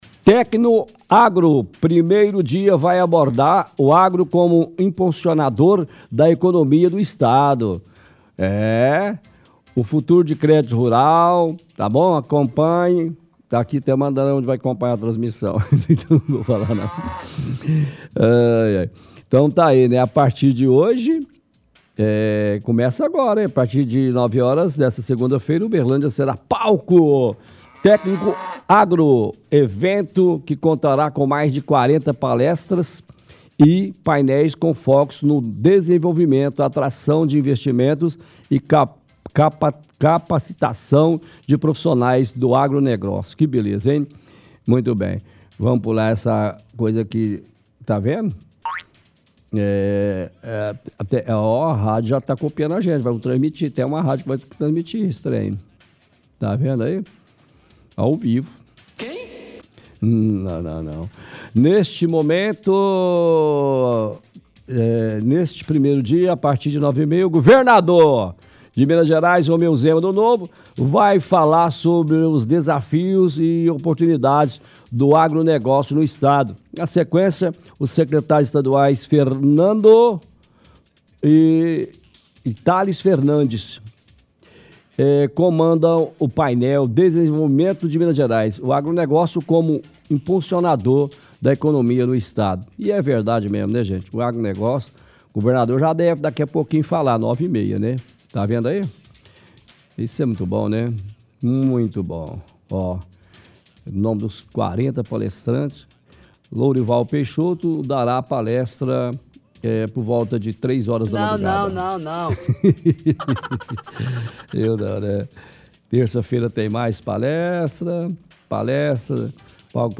lê matéria sobre TecnoAgro. A partir de hoje, às 9h Uberlândia será palco para o evento. Lista o cronograma do evento.